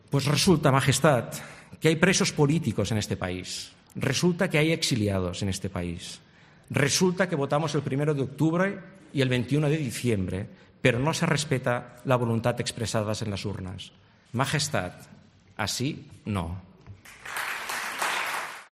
PLENO DE INVESTIDURA
En su discurso durante la primera sesión del debate de investidura, Torra ha aludido a la política exterior de la Generalitat, después de que el Gobierno, a través de la aplicación del artículo 155 de la Constitución, cerrara las llamadas "embajadas" catalanas y creara además un "órgano liquidador" del Diplocat, organismo que finalmente quedó desmantelado en abril.